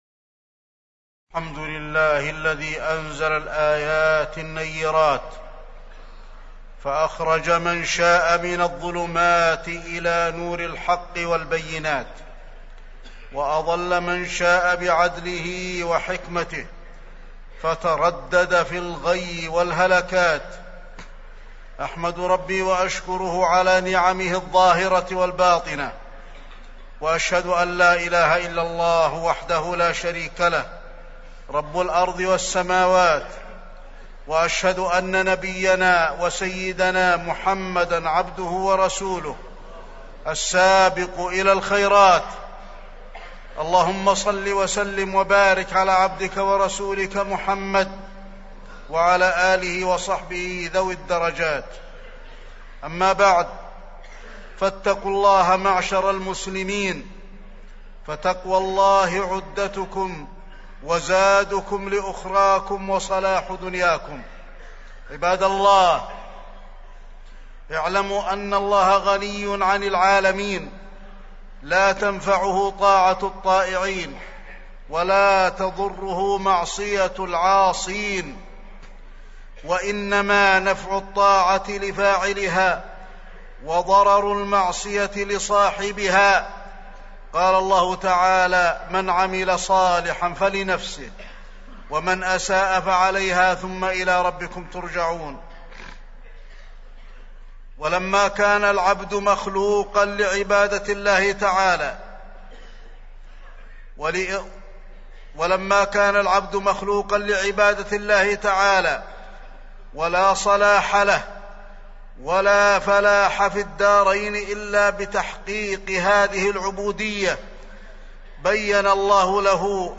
تاريخ النشر ١٧ ذو الحجة ١٤٢٥ هـ المكان: المسجد النبوي الشيخ: فضيلة الشيخ د. علي بن عبدالرحمن الحذيفي فضيلة الشيخ د. علي بن عبدالرحمن الحذيفي طاعة الله واجتناب المعاصي